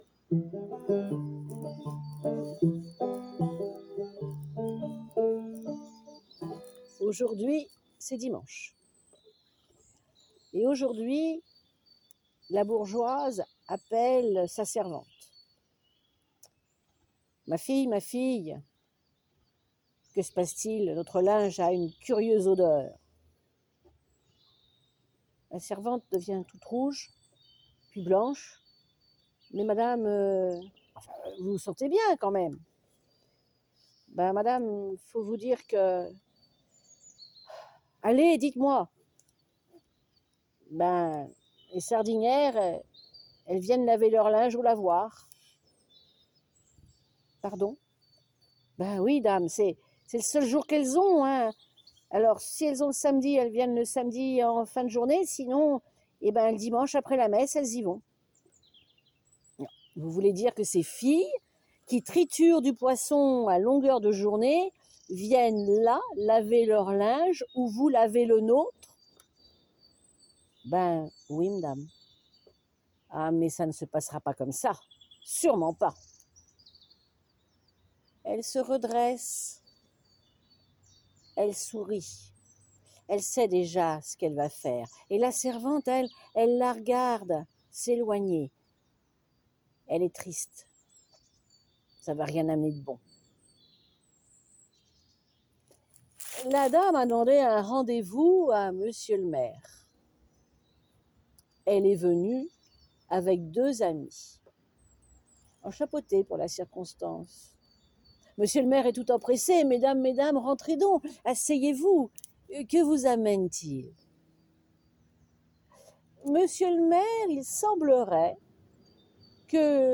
L’association Histoires de mots vous propose 3 contes audio pour une immersion totale dans les paysages vécus et contés de la presqu’île de Quiberon.